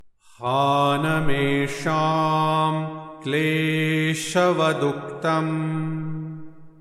Sutra Chanting